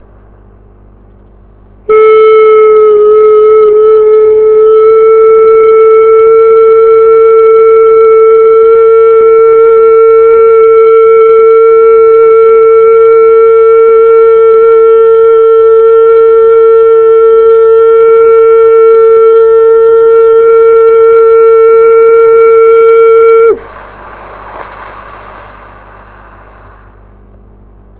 Unica posición, se repite el mantram.
Mantram: IIIIIIIISSSSSSS
Tono: Lo mas agudo posible. Preferiblemente en falsete agudo.